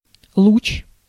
Ääntäminen
IPA : /biːm/ US : IPA : [biːm]